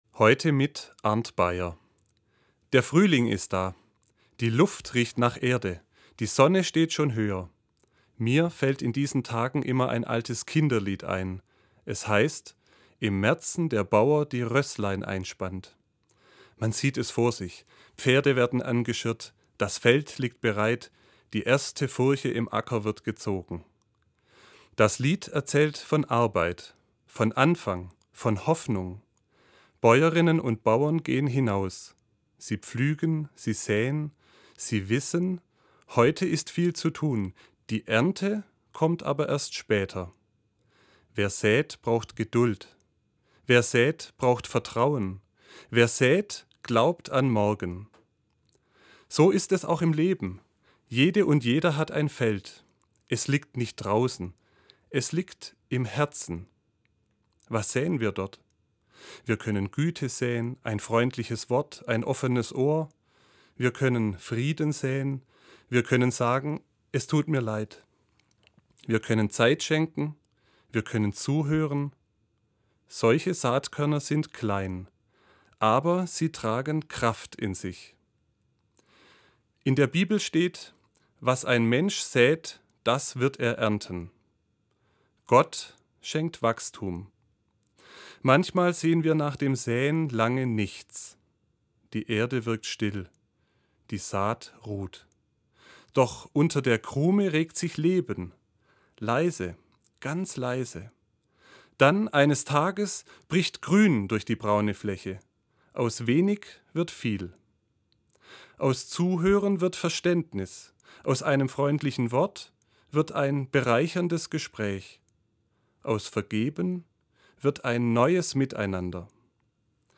An jedem vierten Sonntag im Monat verantwortet die Gebietskirche Süddeutschland eine Sendung im Hörfunkprogramm des Südwestrundfunks: Sie wird im Magazin aus Religion, Kirche und Gesellschaft „SWR1 Sonntagmorgen“ ausgestrahlt, jeweils um 7:27 Uhr (Verkündigungssendung mit 2,5 Minuten Sendezeit).